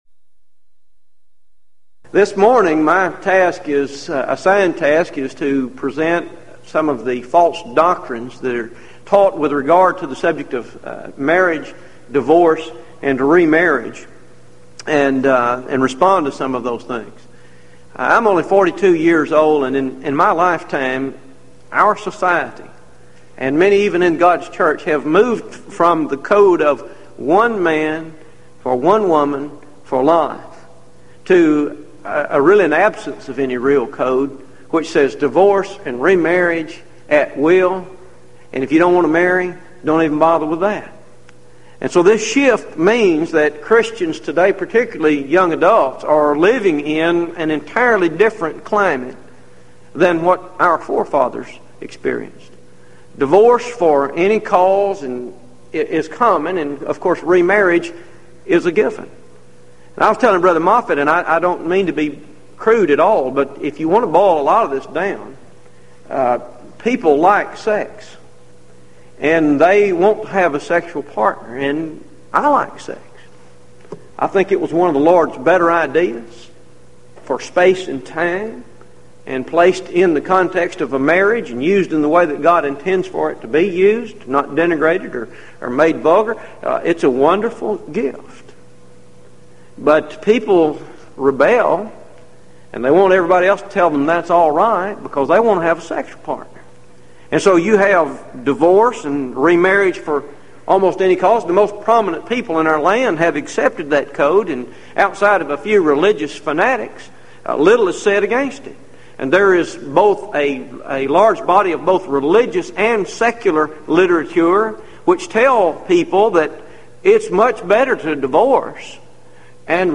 Event: 1997 Gulf Coast Lectures
If you would like to order audio or video copies of this lecture, please contact our office and reference asset: 1997GulfCoast18